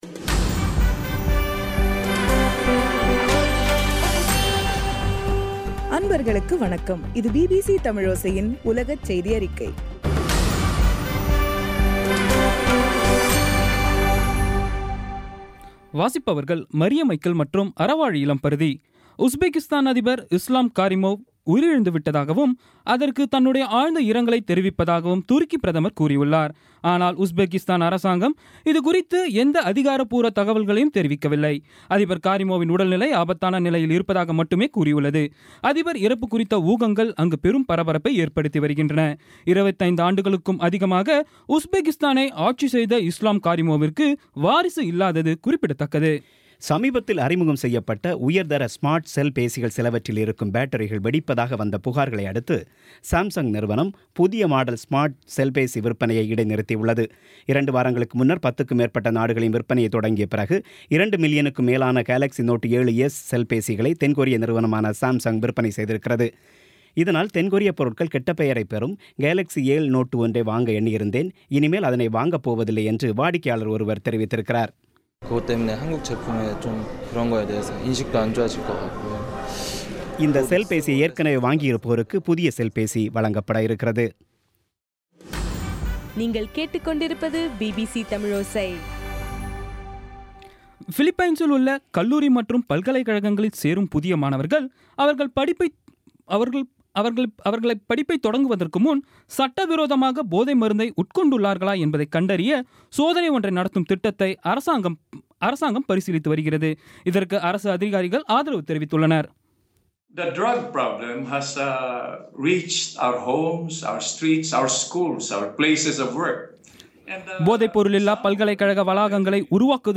பி பி சி தமிழோசை செய்தியறிக்கை (02/09/2016)